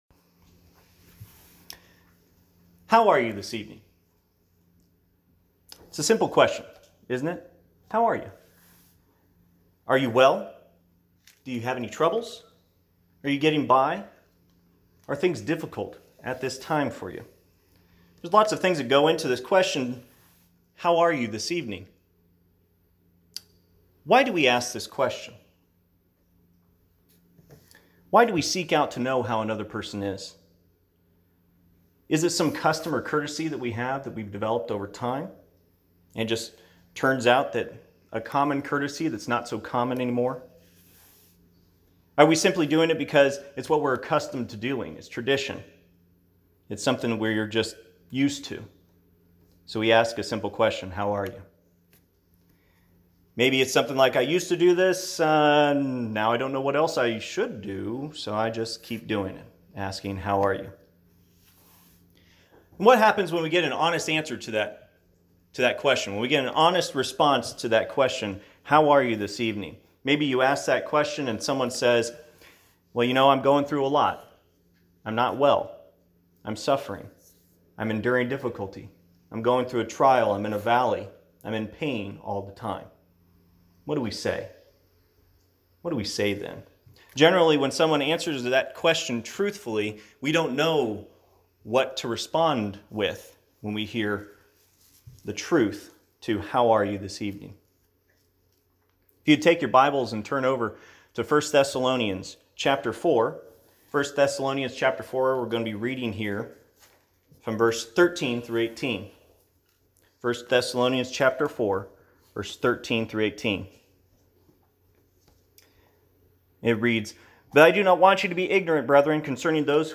1 Thessalonians 4:13-18 Service Type: Sunday PM How we can be an encouragement to one another through difficult times in this life.